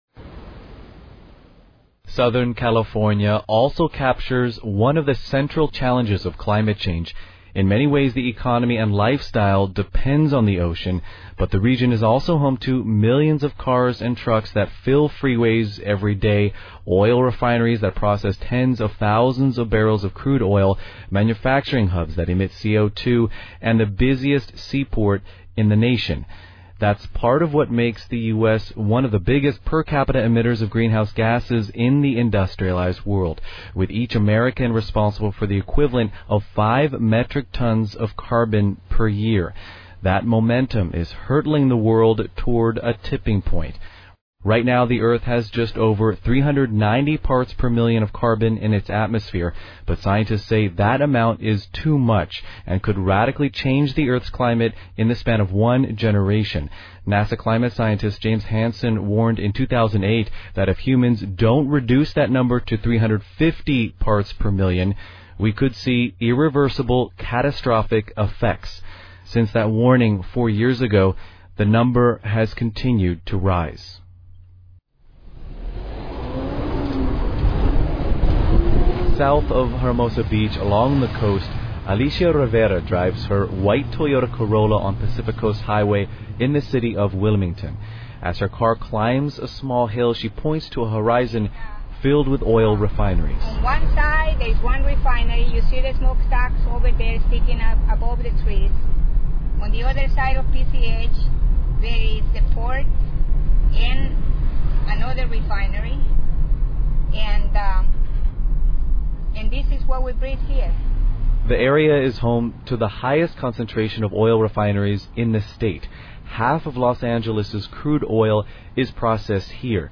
is featured in this Free Speech Radio News documentary about the impact of climate change upon Southern California communities.